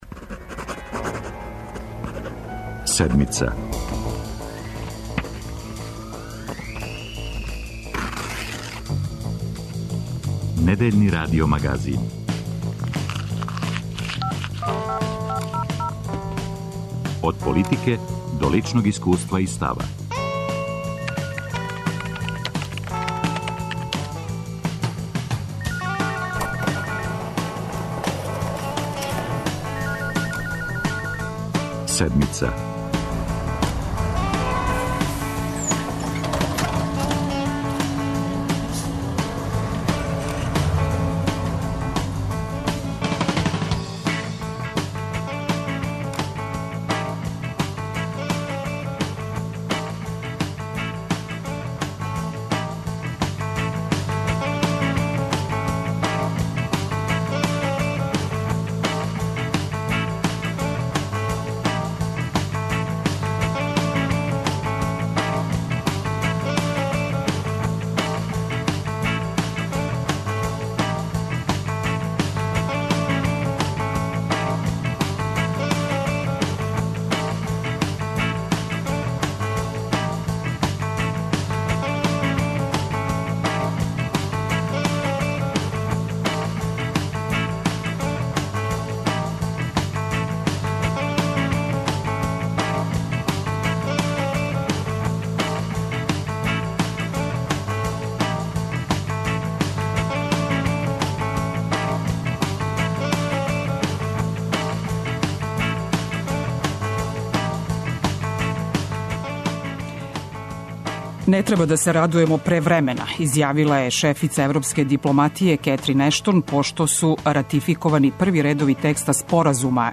Колика год да буде криза, плата и пензија ће бити, обећао за Седмицу потпредседник владе Јован Кркобабић. 'Коме је ПИО фонд дао више, неће морати да враћа, ако се ја питам', поручује још министар.